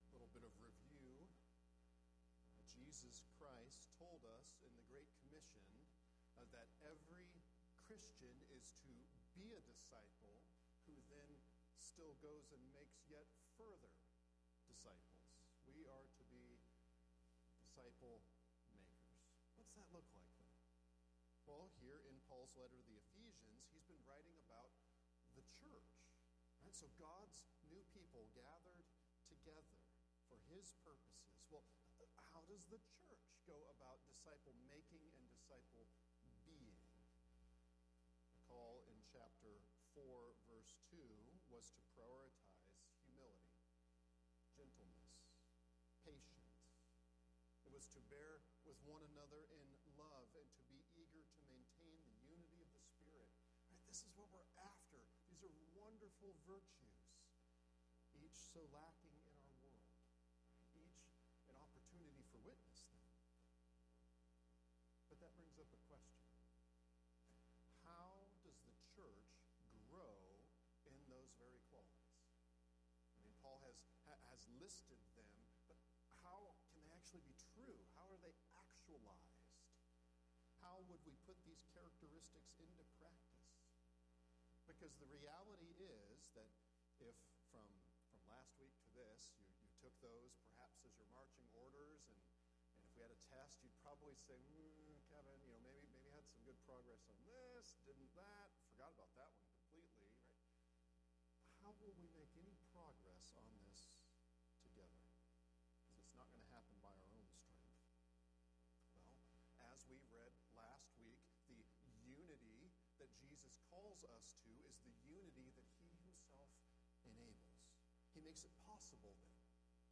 Ephesians 4:11-16 Grow – Sermons